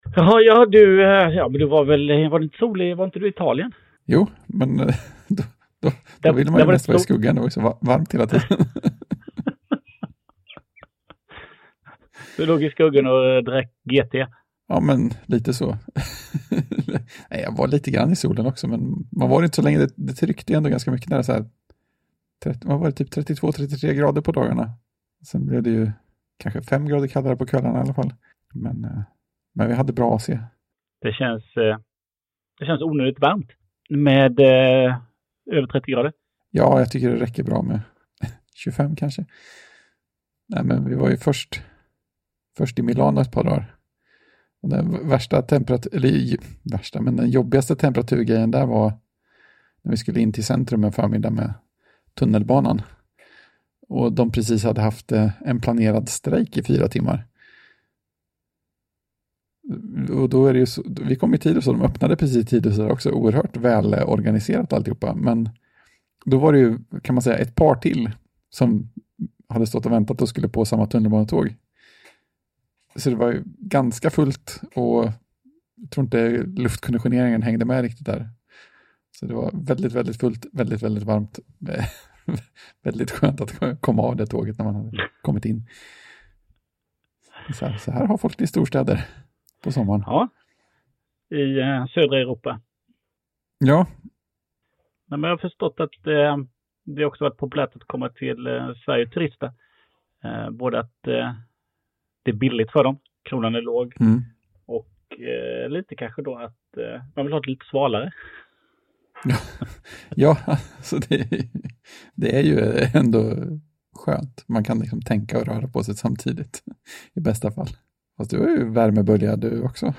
Och ljudet är lite skräpigare än snittet. Vi ber om ursäkt för alla olägenheter.